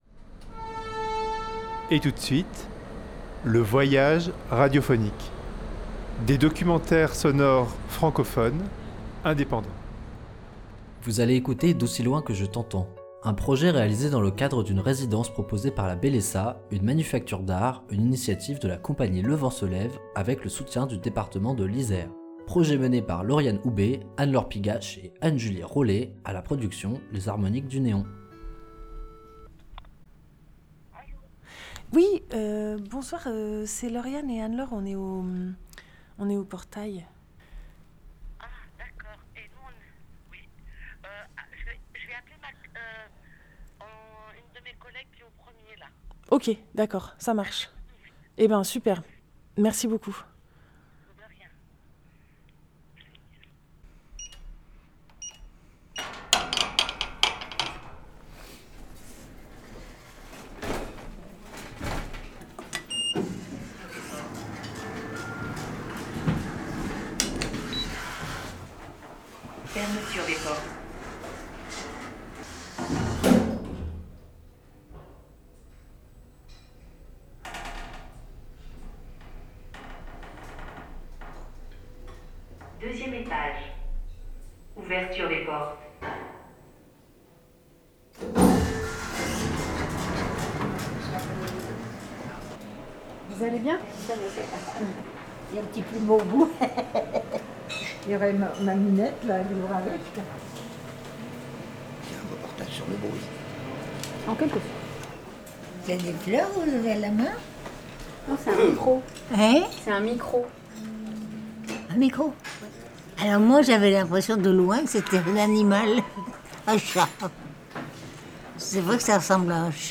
Pendant trois jours, elles ont écouté avec les résidents et les soignantes les enregistrements qu’elles y avaient captés. Une pièce sonore est née de cette résidence hors-du-commun.